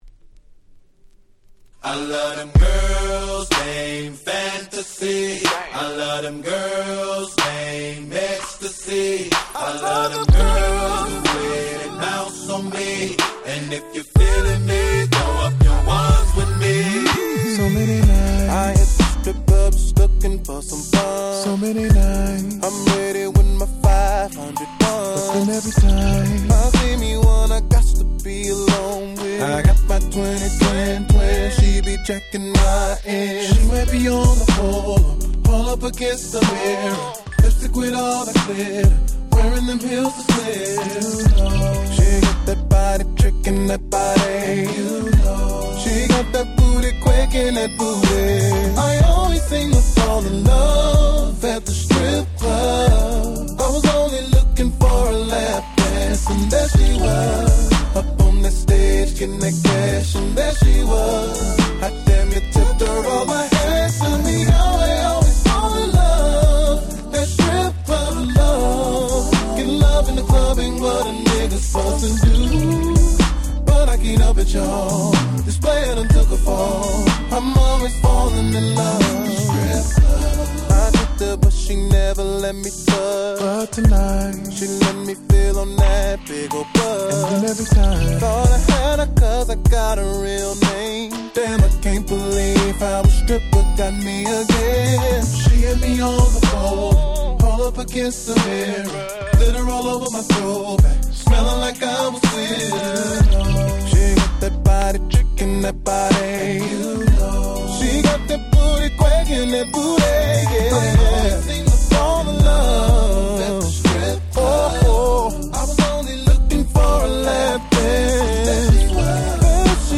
05' Super Nice R&B / Slow Jam !!